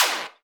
VEC3 Percussion 018.wav